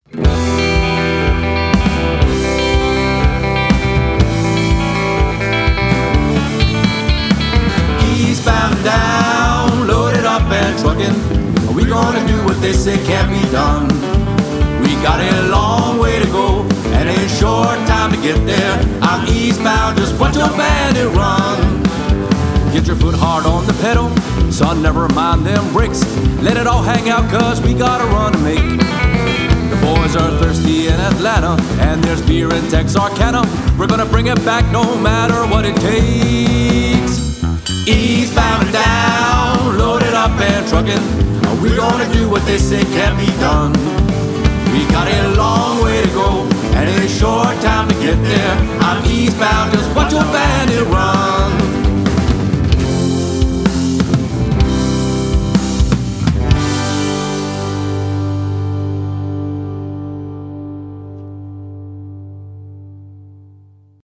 outlaw country band
Core 4-Piece Band with Self Contained P.A & Basic Lights